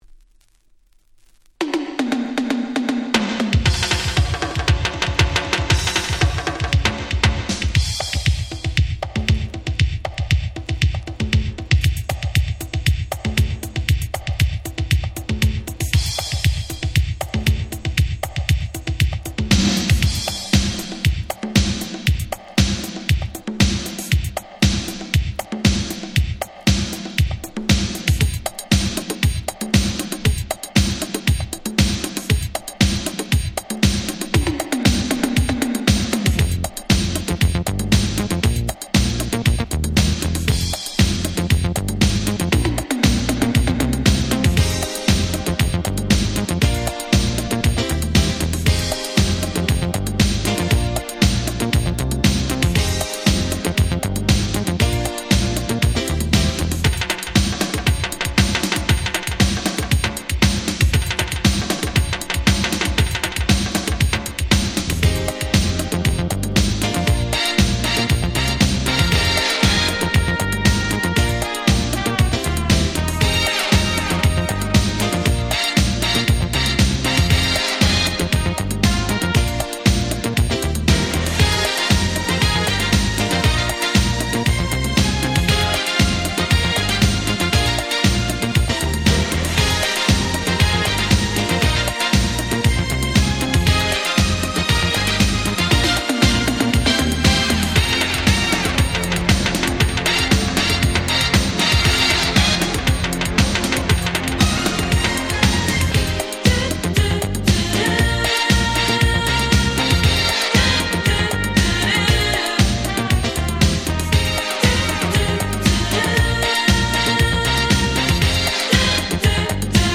88' Super Hit Euro Beat / Disco !!